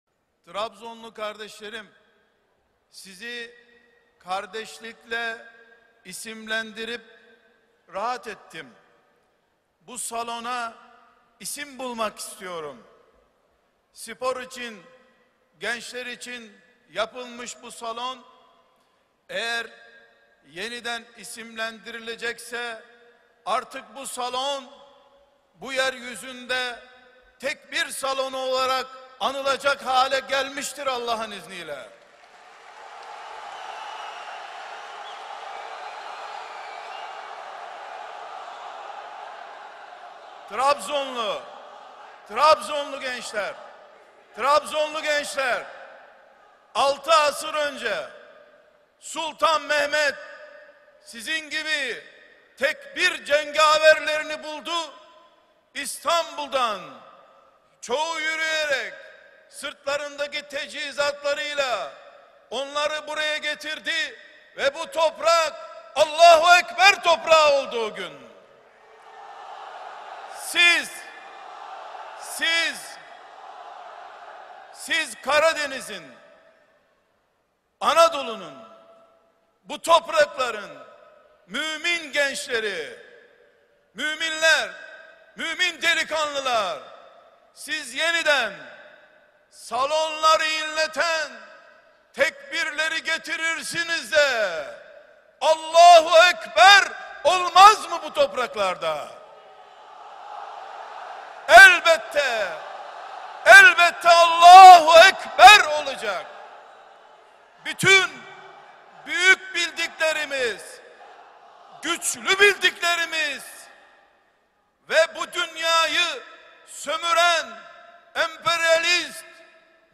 4-dirilis-bulusmalari-trabzon-konusmasi.mp3